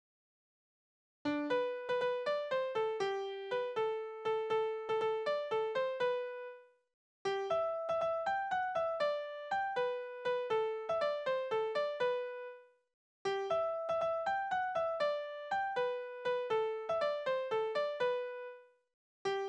Naturlieder
Tonart: G-Dur
Taktart: 6/8
Tonumfang: Undezime
Besetzung: vokal
Anmerkung: es fehlt der Schlusston nach der Wiederholung